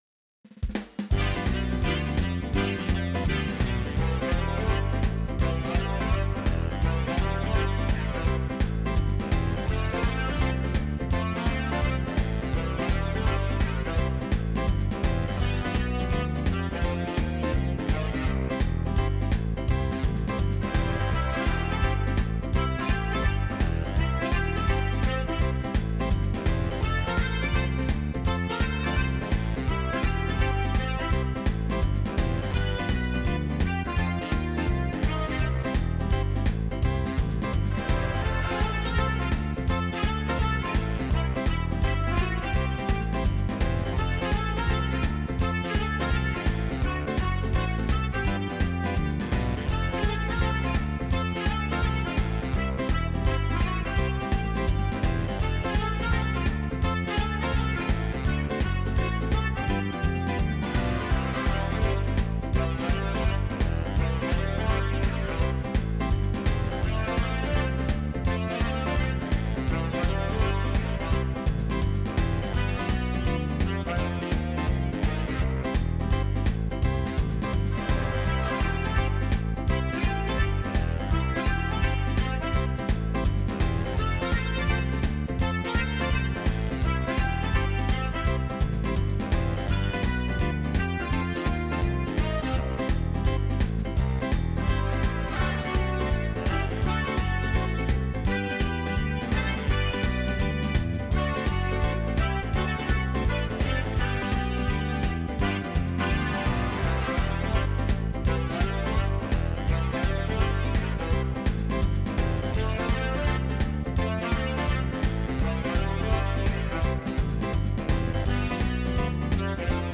Jive